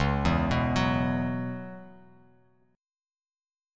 Listen to Dbm11 strummed